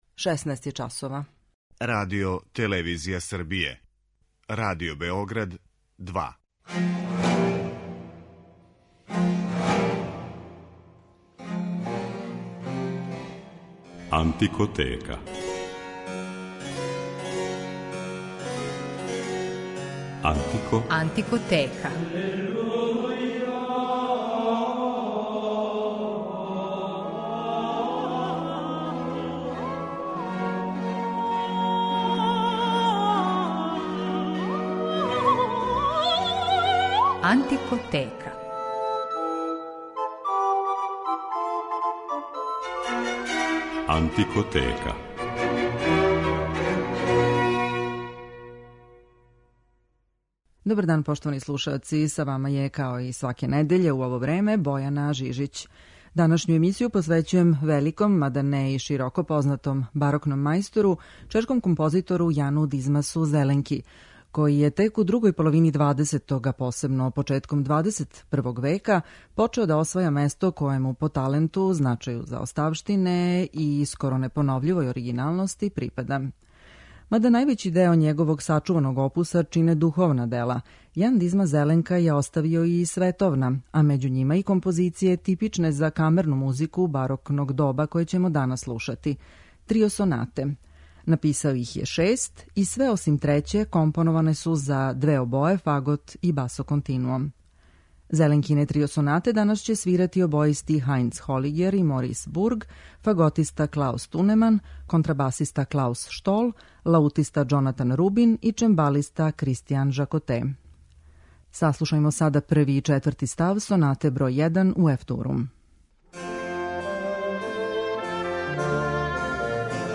камерну музику барокног доба